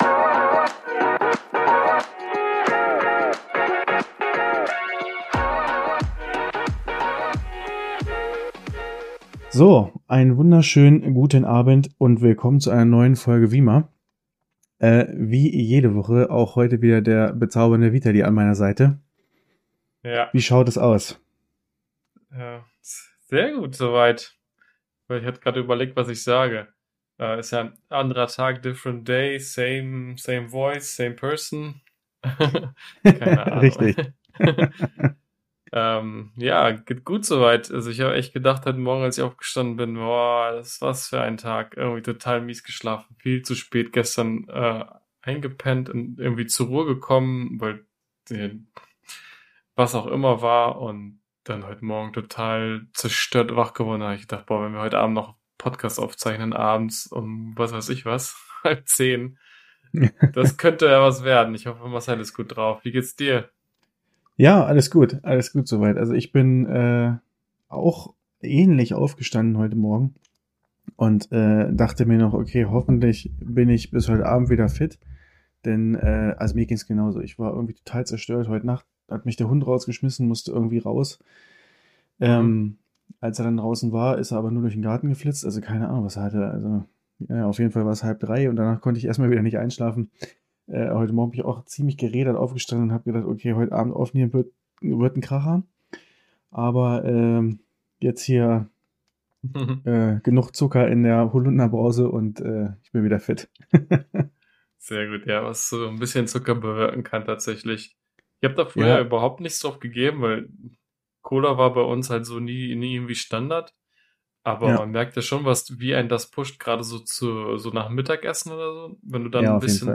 Falls ihr Lust auf ein bisschen entspannten Talk habt, dann hört uns doch einfach dabei zu!